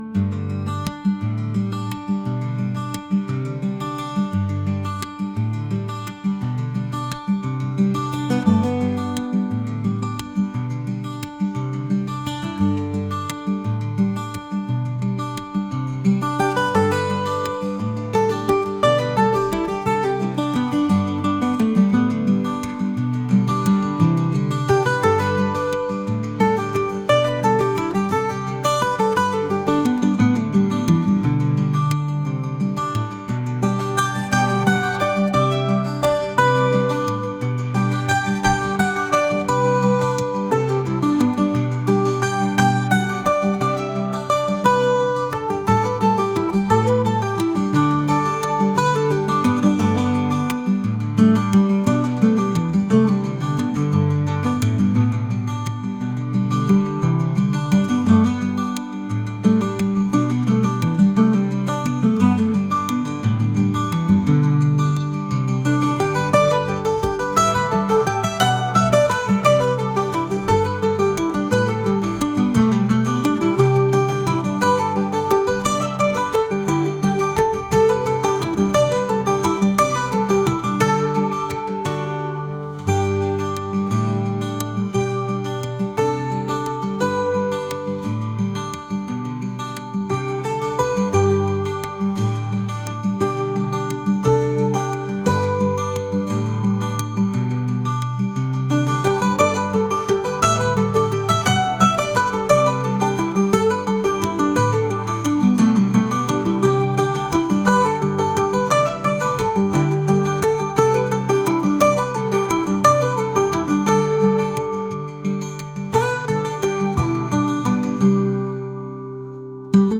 acoustic | pop | indie